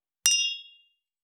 262,ショットグラス乾杯,乾杯,アルコール,バー,お洒落,モダン,カクテルグラス,ショットグラス,おちょこ,テキーラ,シャンパングラス,カチン,チン,カン,ゴクゴク,プハー,シュワシュワ,コポコポ,ドボドボ,トクトク,カラカラ,ガシャーン,クイッ,ジュワッ,パチパチ,ドン,ザブン,バシャ,コツン,タン,ポン,効果音,環境音,BGM,
コップ